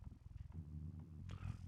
• demonic techno voice "technology".wav
Changing the pitch and transient for a studio recorded voice (recorded with Steinberg ST66), to sound demonic/robotic.